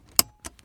seatbelt-on.mp3